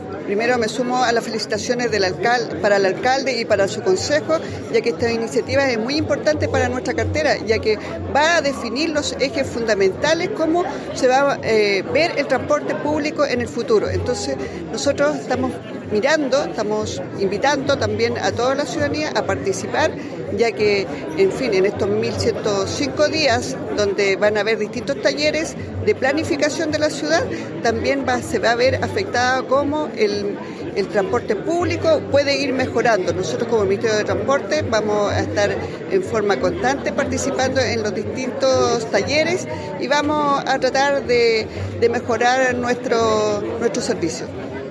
La actividad, realizada en la sala de Concejo de la Municipalidad de Temuco, reunió a autoridades comunales, regionales, ancestrales, dirigentes y dirigentas sociales, además de representantes del mundo público y privado.
Mary-Valdebenito-seremi-Transportes-Araucania.mp3